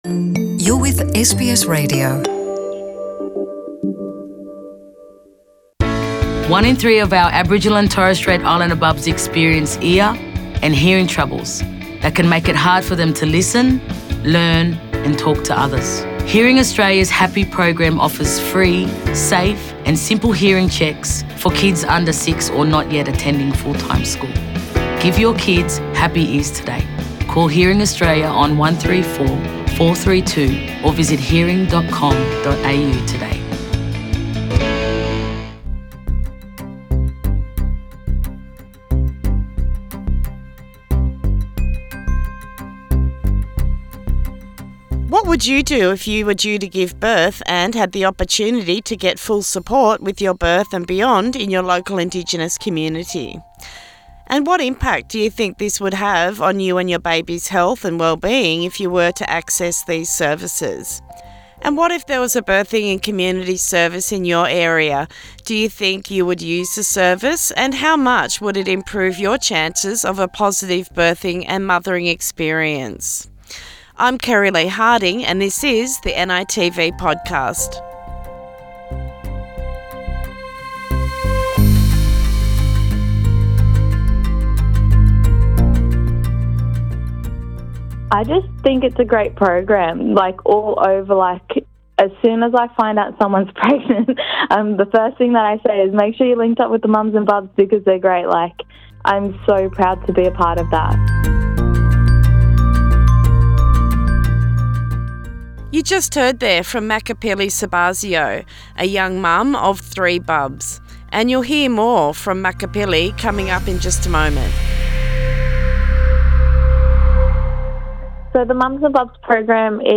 In this episode of The NITV Podcast we explore the birthing in our communities and yarn all things mum’s and bub’s and how census data helps support programs. Interviews